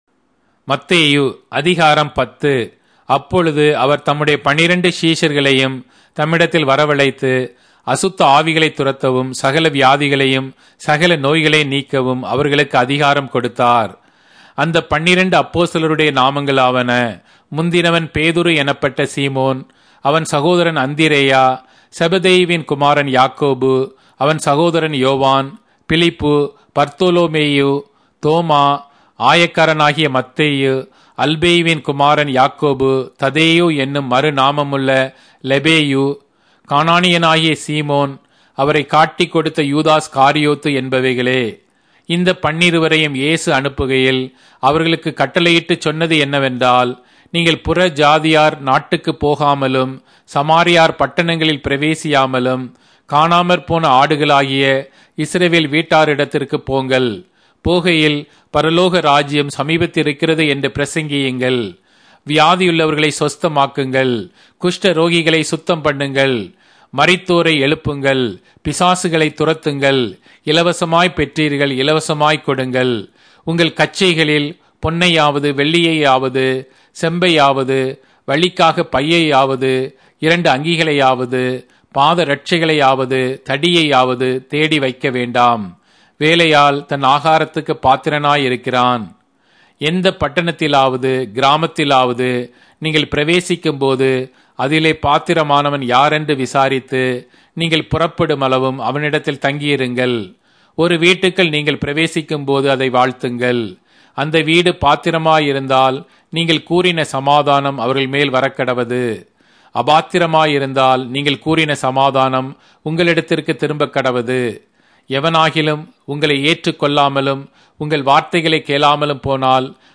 Tamil Audio Bible - Matthew 19 in Mhb bible version